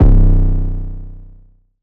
TS - 808 (5).wav